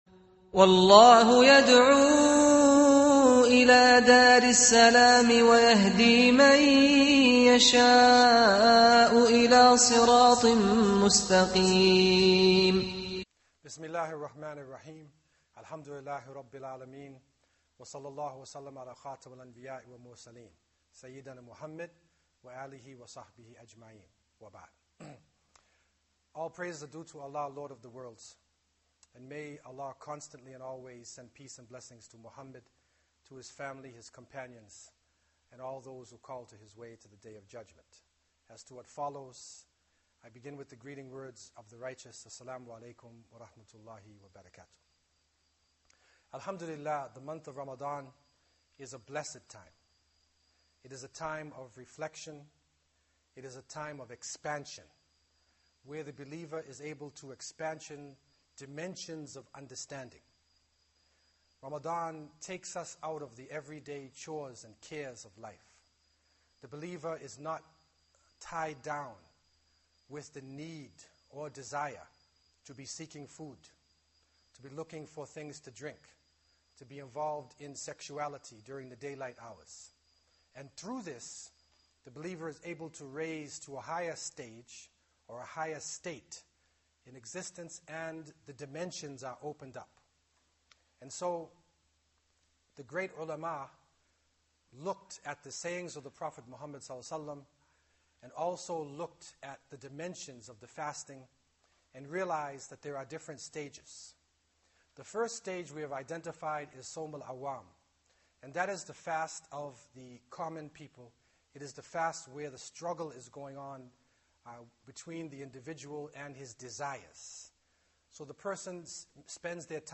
Most Muslims think of Ramadan as a time to abstain from food and drink, but the scholars of Islam recognized three distinct dimensions of fasting — each taking the believer to progressively higher levels of spiritual purification. In this profound lecture, the diseases of the heart that undermine our worship are exposed, and the path to achieving the highest level of fasting is laid out with clarity and urgency.